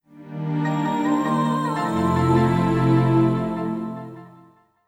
MinderiaOS Delt Shutdown.wav